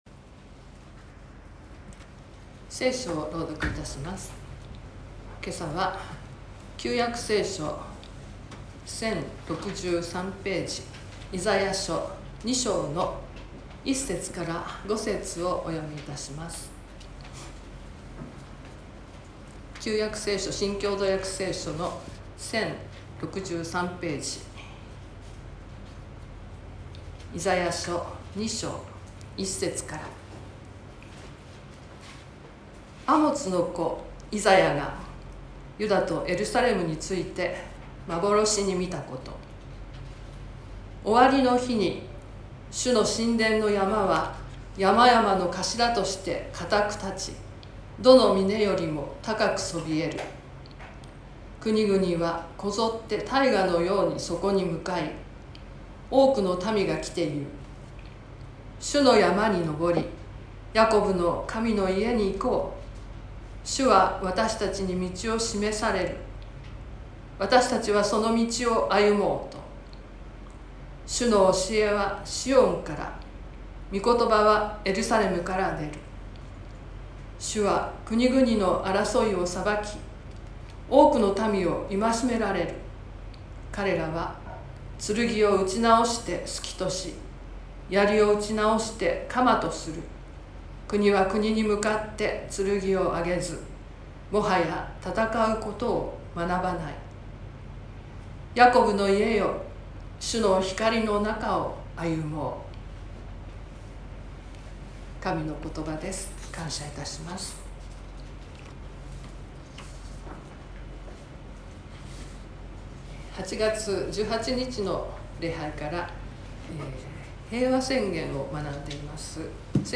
Youtubeで直接視聴する 音声ファイル 礼拝説教を録音した音声ファイルを公開しています。